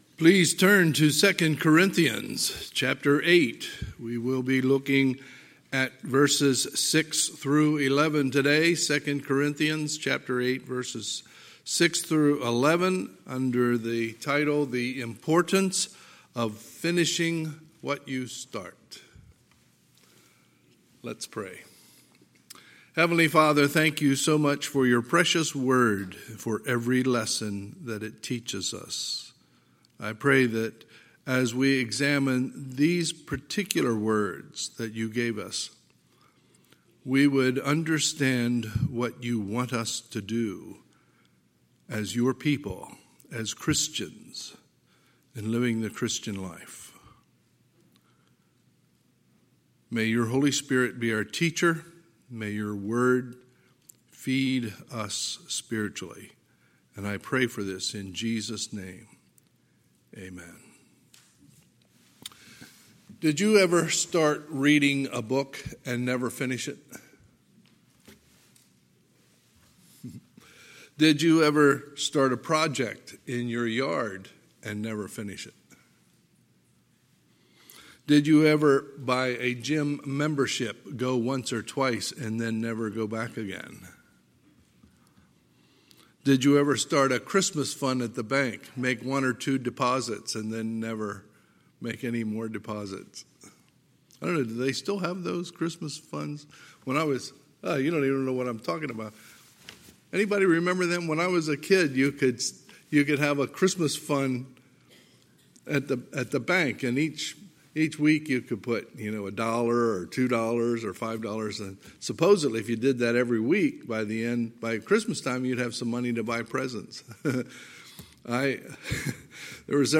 Sunday, August 16, 2020 – Sunday Morning Service